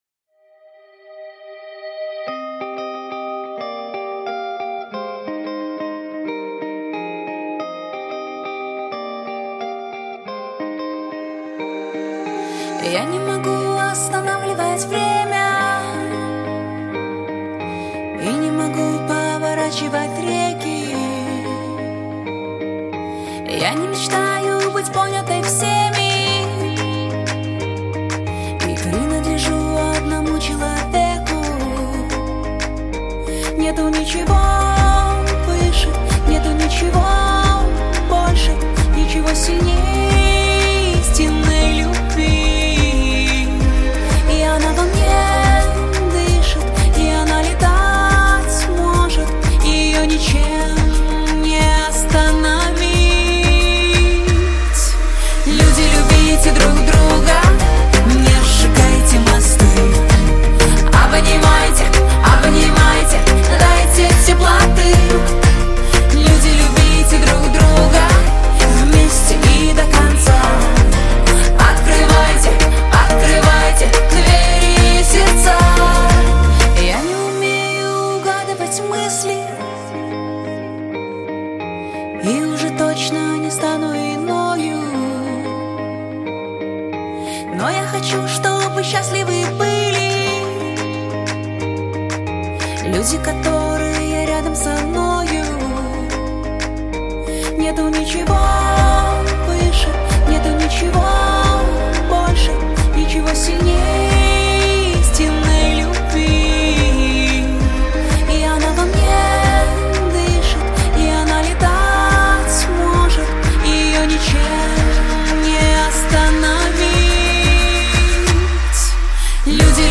Жанр: Поп-музыка / Саундтреки